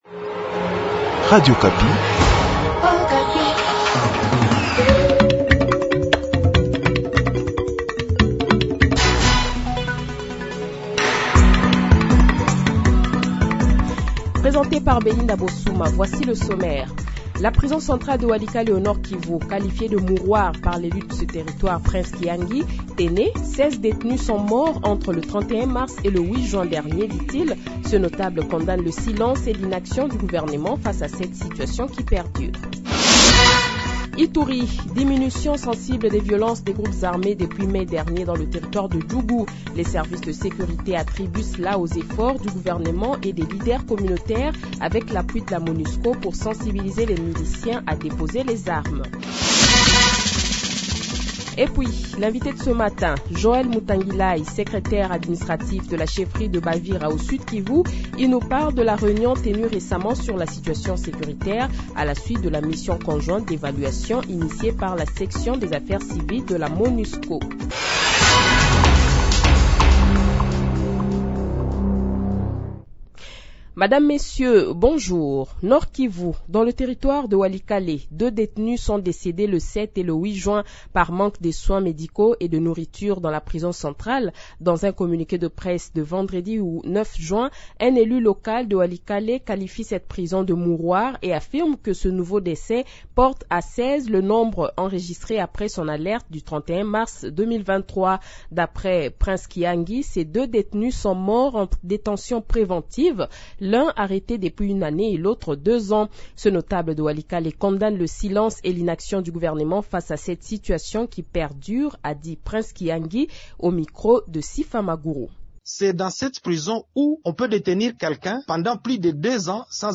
Journal Matin
Le Journal de 7h, 11 Juin 2023 :